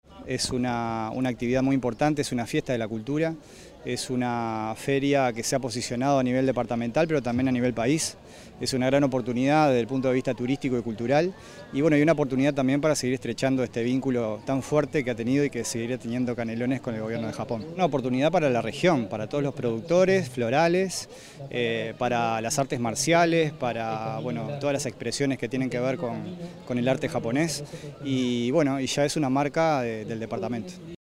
sergio_machin_director_de_cultura.mp3